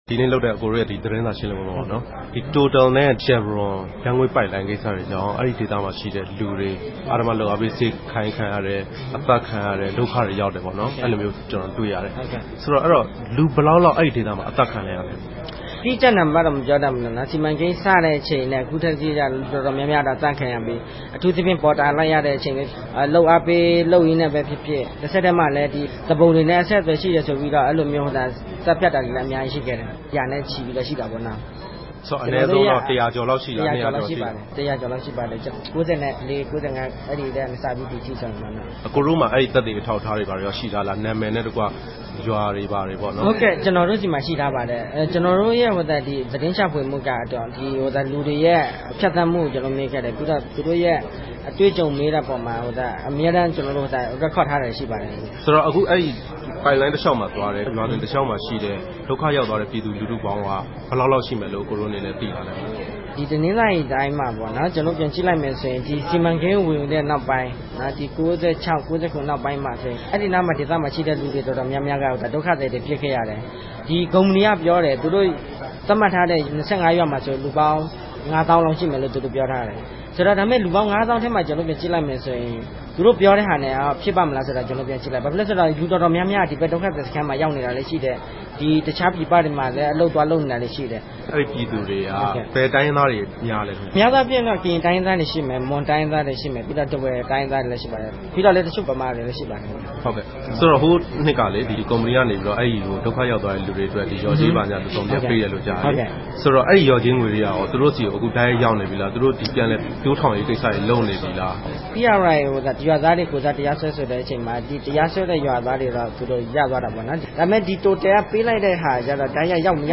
တြေႚဆုံမေးူမန်းခဵက်။